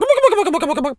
turkey_ostrich_gobble_10.wav